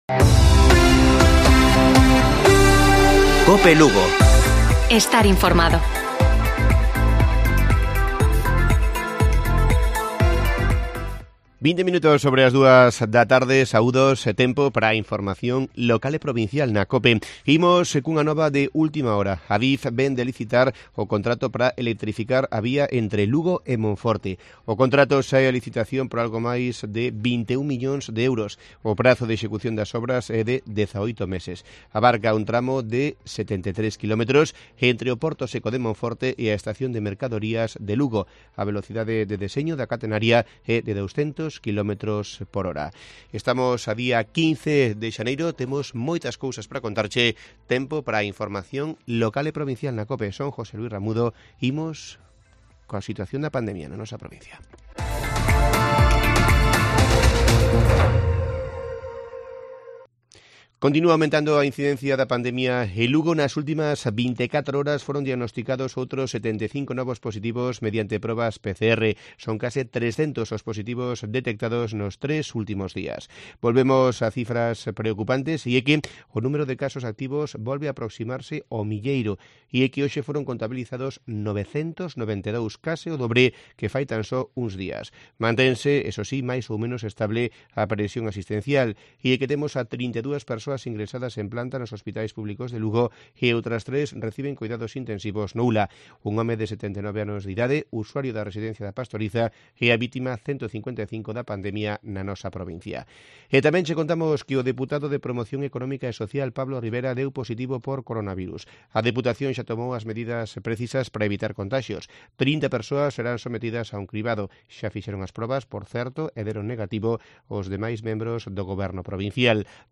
Informativo Mediodía de Cope Lugo. 15 de enero. 14.20 horas